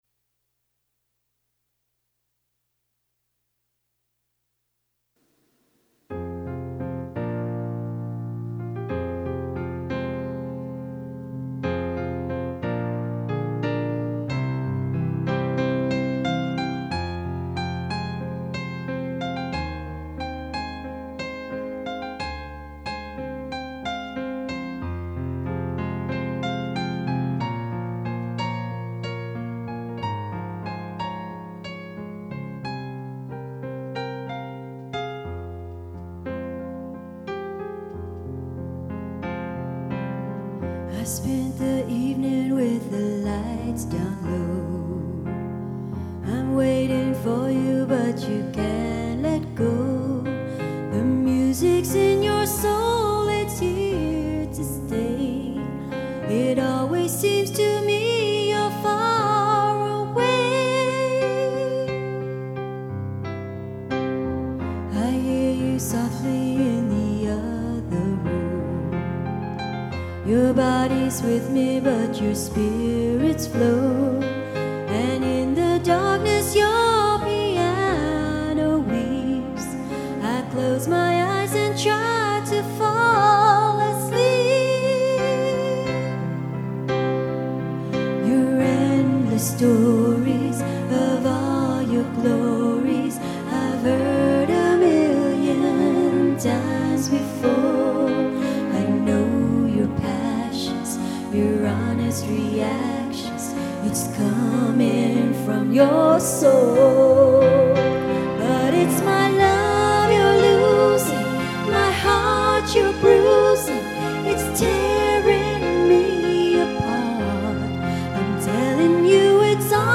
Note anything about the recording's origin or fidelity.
This is just a demo. Can you believe it was a live first take, with only one other track added afterwards to provide the vocal harmony? 🙂